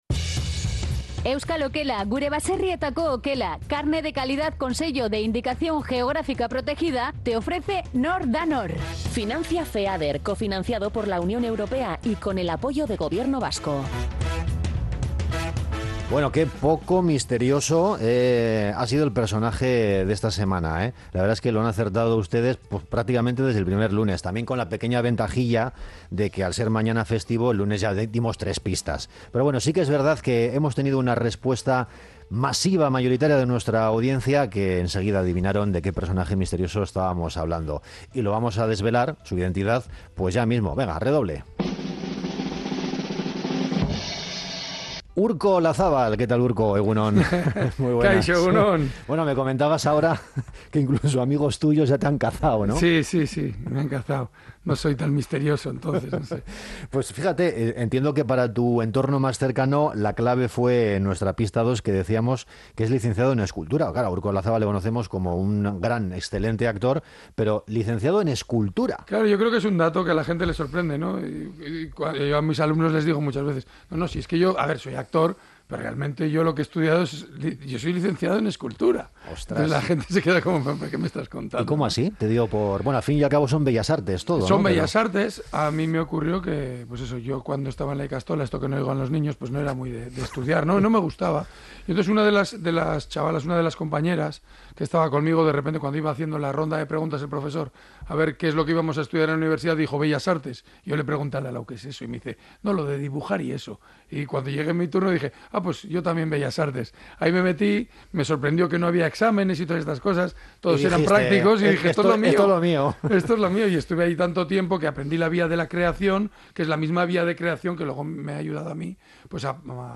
Morning show conectado a la calle y omnipresente en la red.